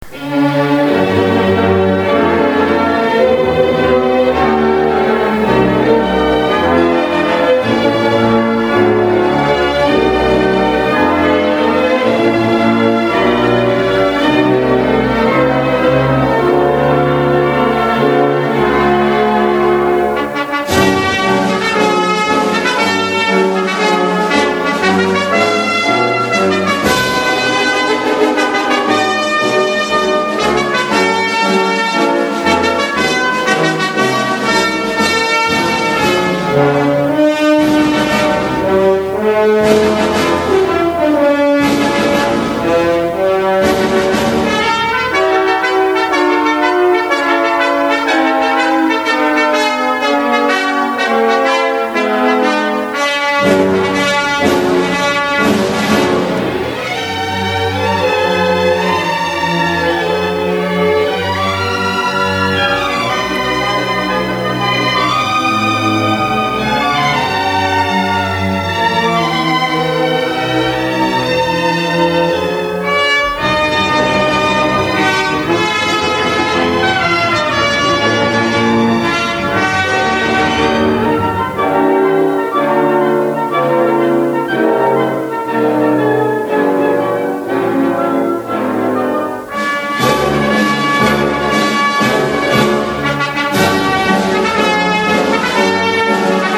では「冒険の旅」をオーケストラ・バージョンでお届けしましょう。
ライブ盤です。